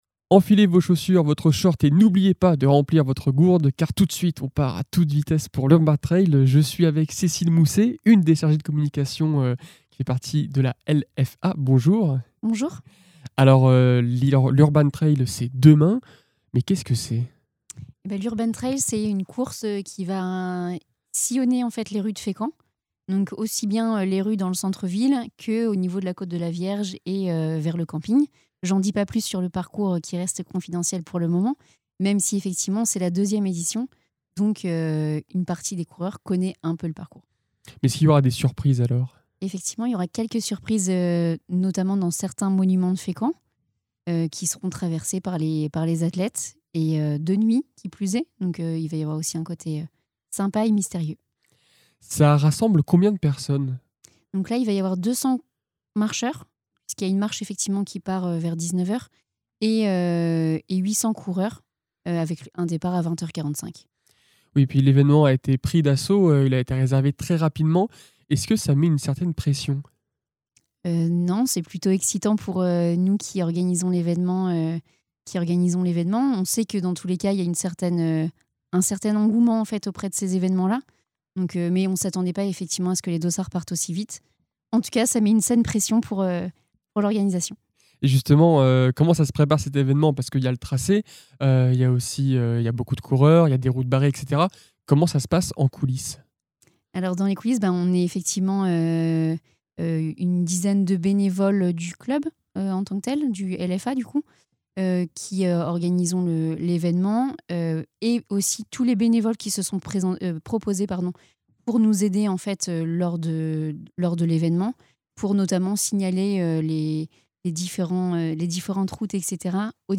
Les interviews Radar Actu Interview fécamp podcast